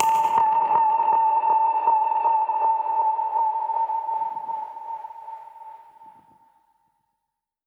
Index of /musicradar/dub-percussion-samples/125bpm
DPFX_PercHit_A_125-05.wav